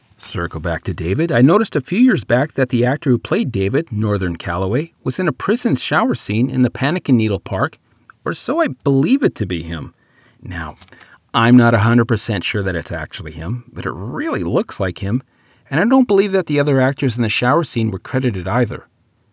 This is -14dB LUFS with -1dB gentle peak limit.
This is a variation on Audiobook Mastering.
– Rumble Filter to get rid of any low pitch trash.
– Loudness Normalization to -14dB LUFS.